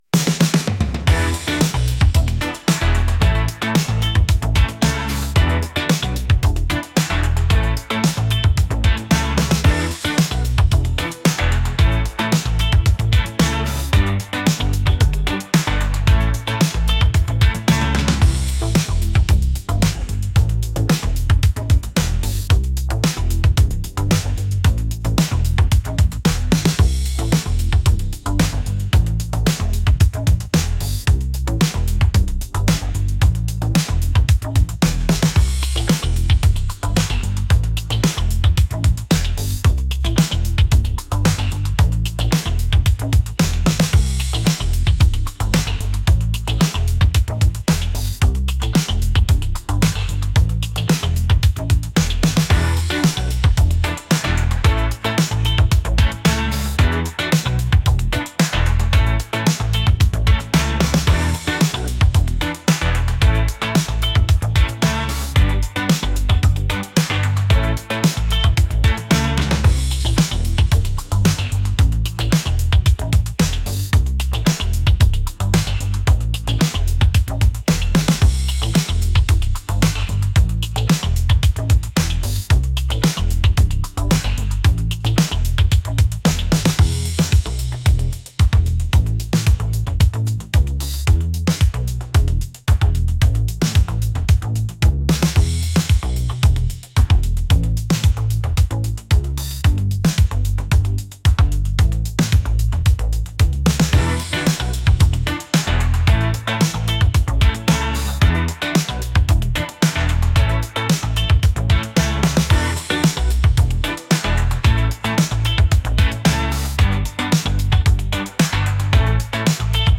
retro | upbeat | pop